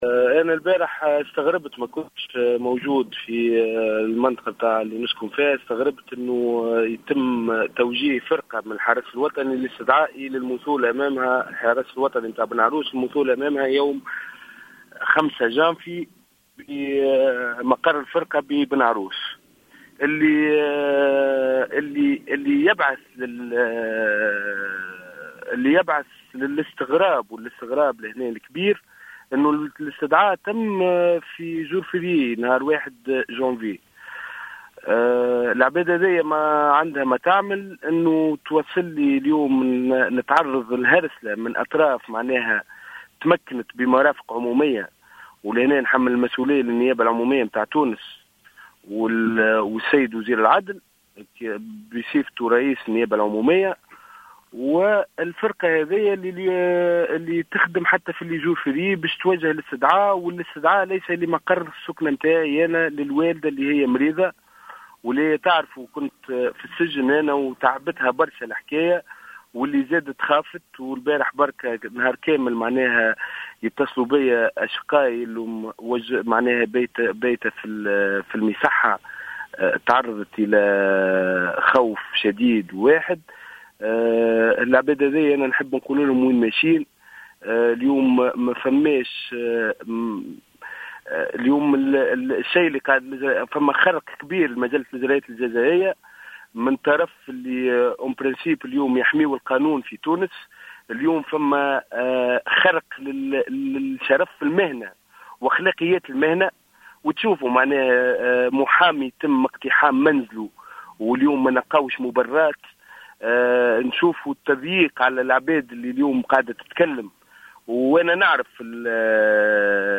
في تصريح هاتفي للجوهرة أف أم